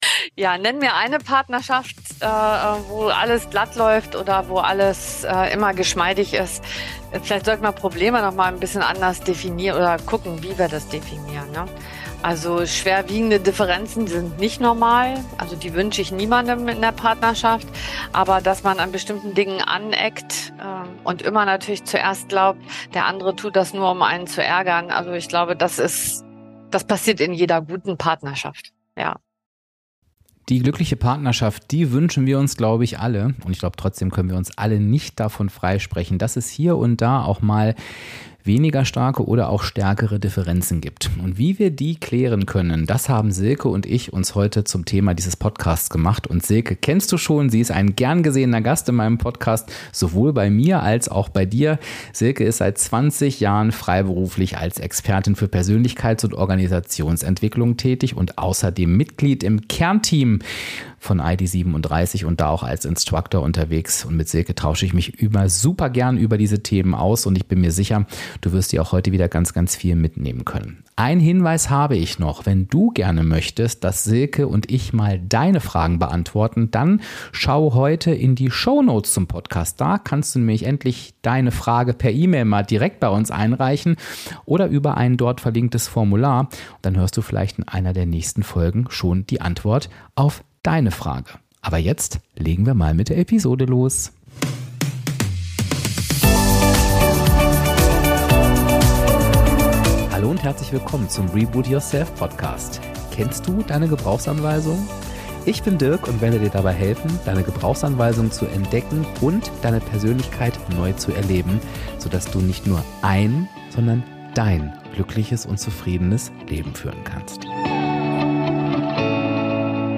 Sind Probleme in einer Partnerschaft wirklich „normal“? Ein Gedankenaustausch